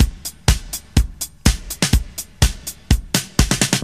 124 Bpm Drum Groove A# Key.wav
Free drum groove - kick tuned to the A# note.
.WAV .MP3 .OGG 0:00 / 0:04 Type Wav Duration 0:04 Size 659,67 KB Samplerate 44100 Hz Bitdepth 16 Channels Stereo Free drum groove - kick tuned to the A# note.
124-bpm-drum-groove-a-sharp-key-1cI.ogg